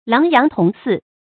發音讀音
成語拼音 láng yáng tóng sì